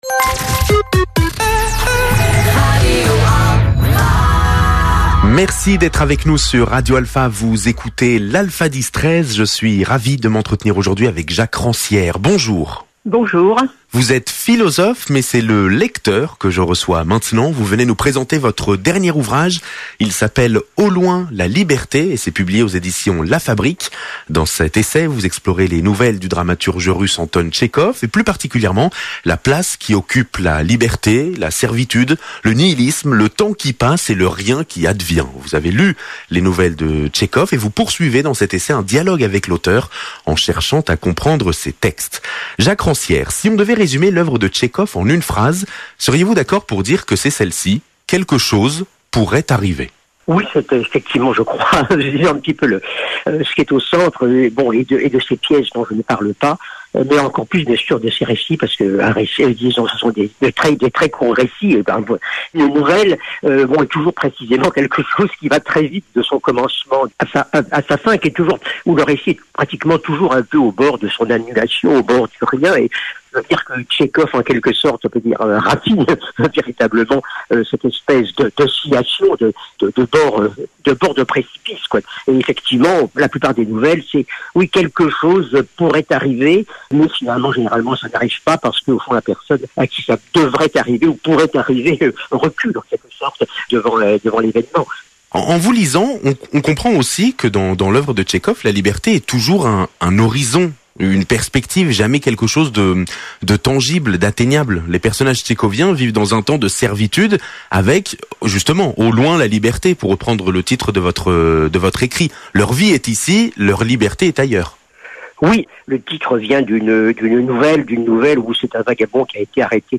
Interview-Jacques-Ranciere.mp3